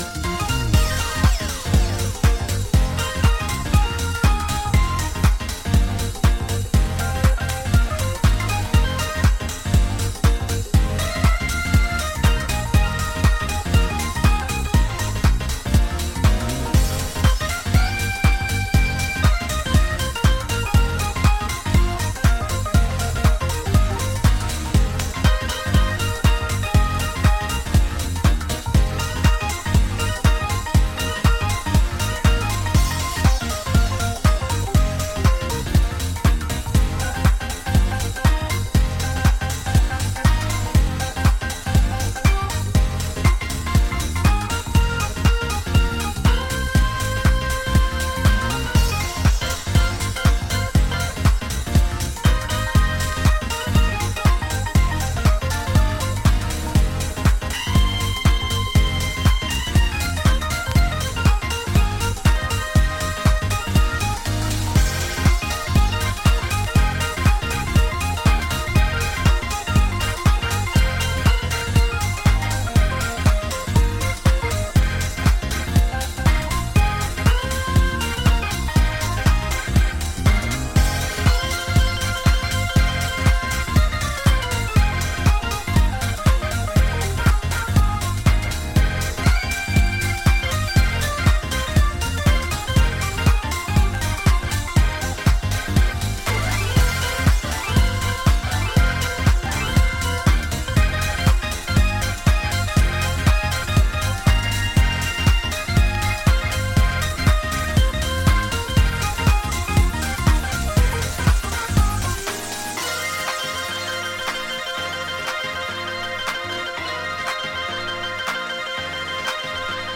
躍進の止まらない彼からエネルギーを貰える、温かなジャジー・ディープハウス集です。